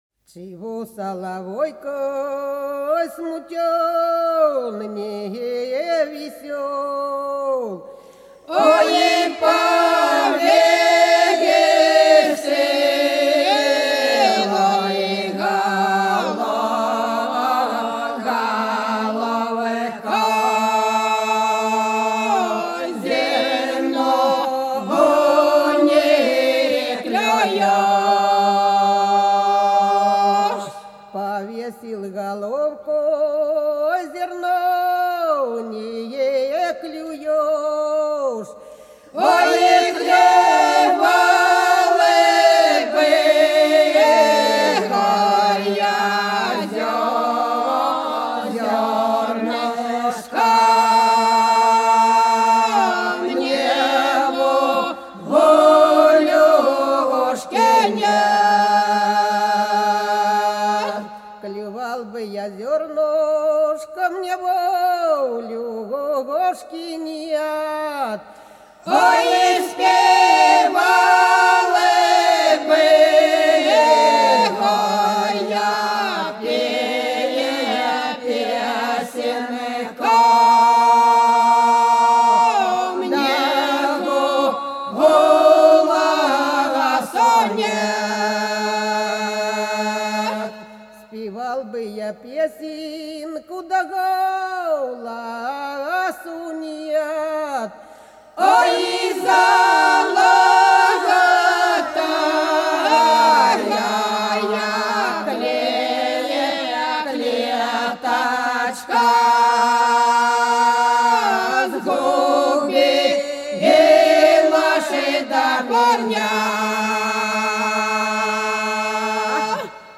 Хороша наша деревня Чего, соловейка, смутен, невесел - протяжная (с. Репенка)